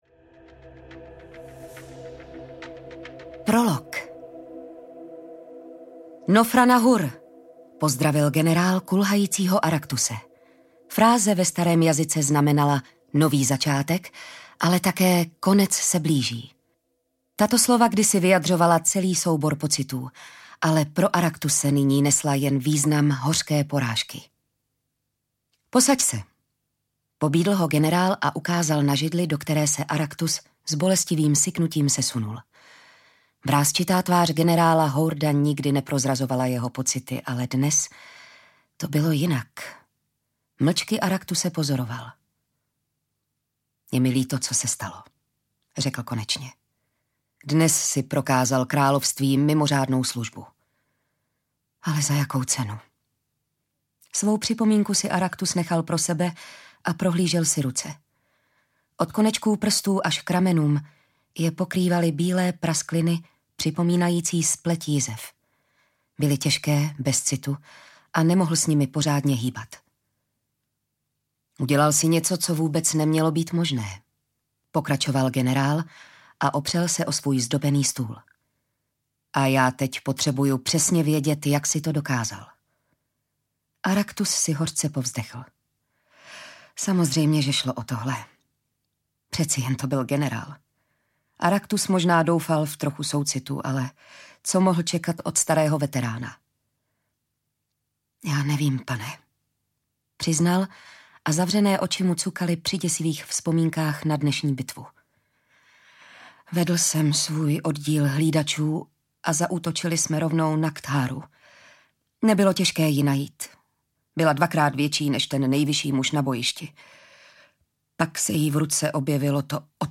Arila: Stříbrné město audiokniha
Ukázka z knihy
• InterpretJitka Ježková